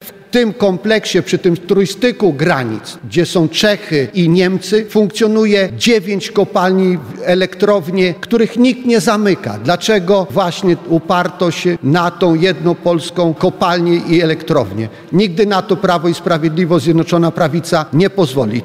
O zagrożeniu bezpieczeństwa energetycznego mówili w Puławach parlamentarzyści Prawa i Sprawiedliwości. Chodzi o problemy z kompleksem energetycznym w Turowie.
Tu chodzi o nasze bezpieczeństwo energetyczne – mówi poseł PiS, Sławomir Skwarek.